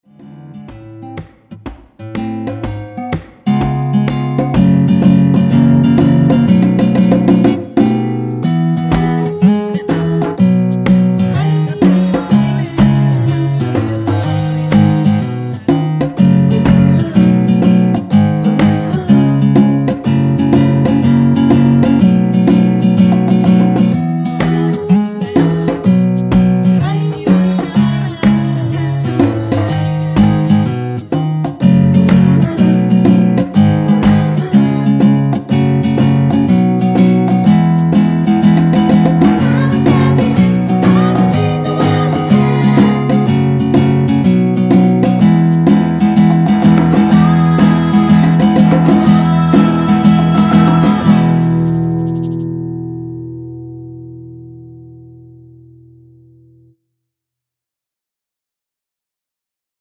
전주 없이 시작 하는곡이라 노래 하시기 편하게 전주 2마디 많들어 놓았습니다.(일반 MR 미리듣기 확인)
원키 코러스 포함된 MR입니다.
앞부분30초, 뒷부분30초씩 편집해서 올려 드리고 있습니다.